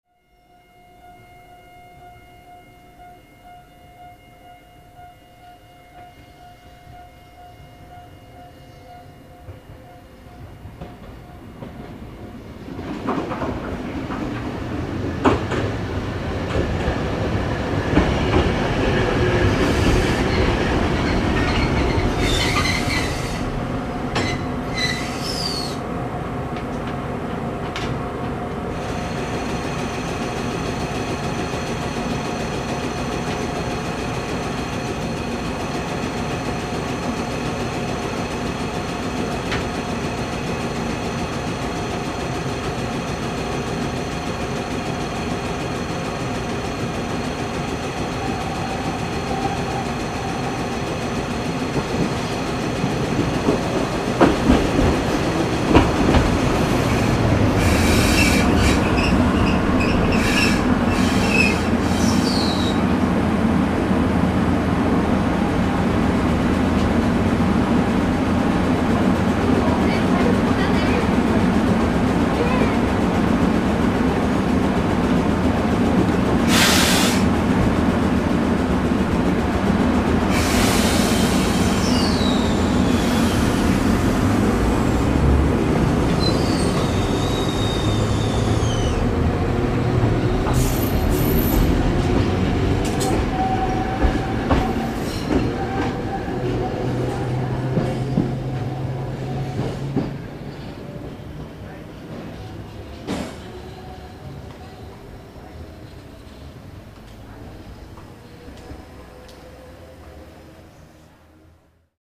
北野線はほとんど単線で、この竜安寺駅ですれ違うようになっています。
竜安寺駅に入る帷子ノ辻行き。
ryouanji-2.mp3